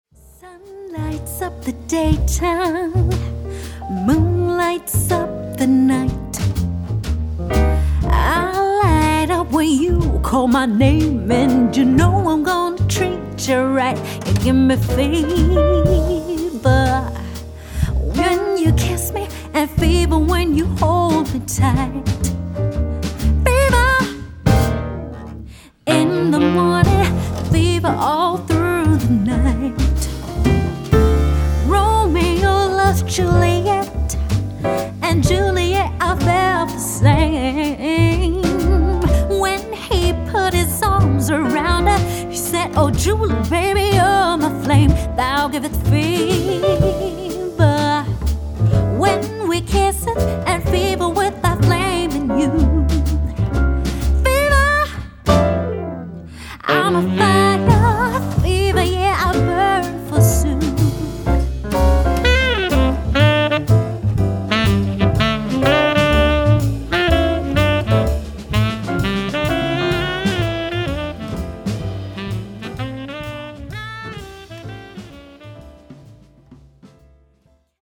Vip Lounge Music